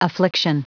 Prononciation du mot affliction en anglais (fichier audio)
Prononciation du mot : affliction